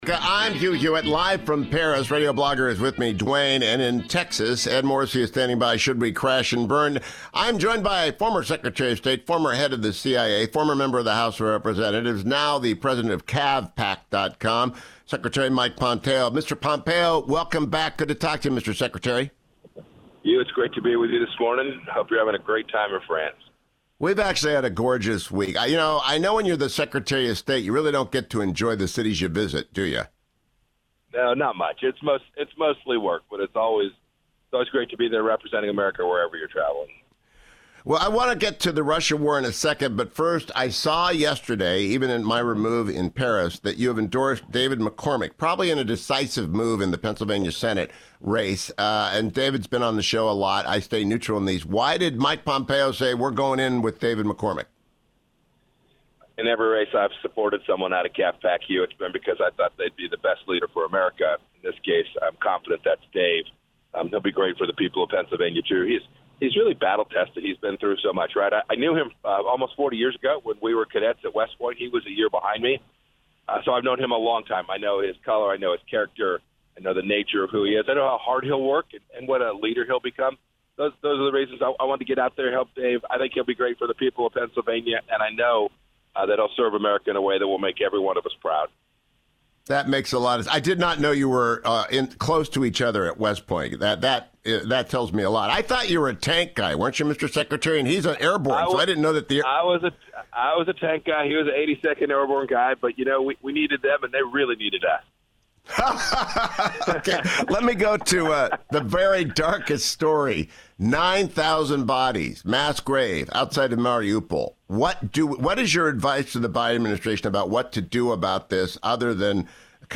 Former Secretary of State Mike Pompeo joined me this AM: